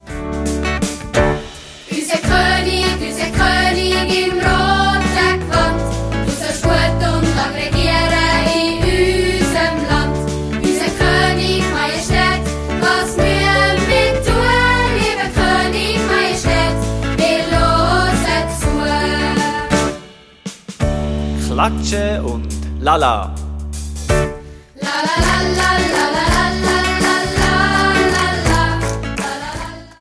Schulmusical